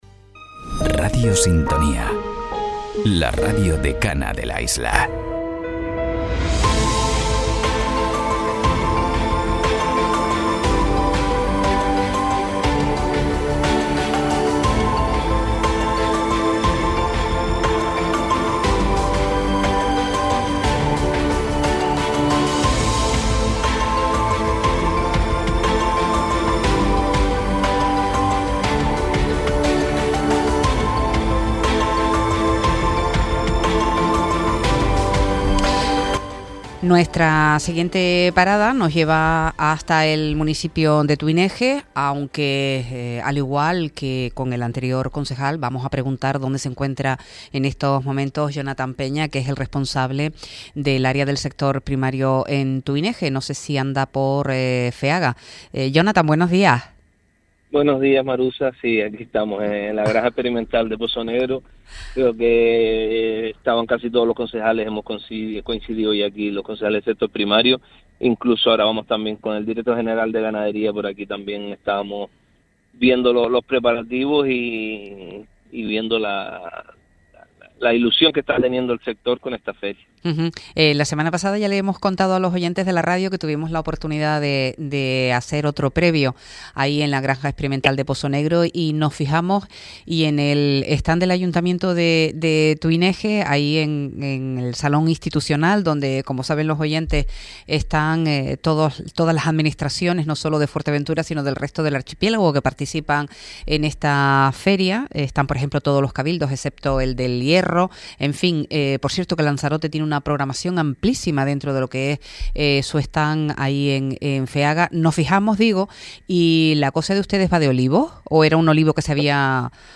Entrevistas Ayuntamiento de Tuineje rinde homenaje al olivo en Feaga 2026 Reproducir episodio Pausar episodio Mute/Unmute Episode Rebobinar 10 segundos 1x Fast Forward 30 seconds 00:00 / 00:22:48 Suscribir Compartir Feed RSS Compartir Enlace Incrustar